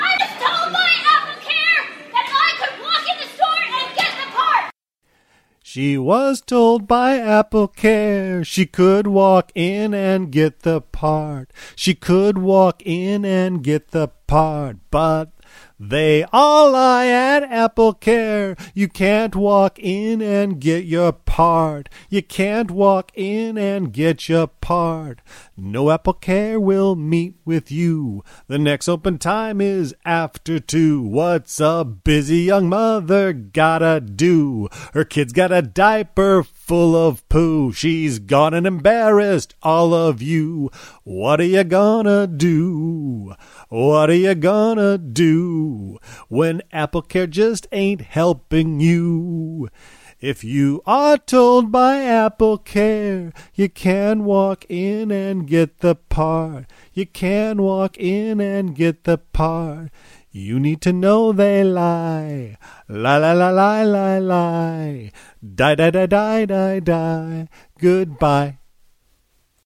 Just because I don’t have a groovy sound machine I sang it a cappella!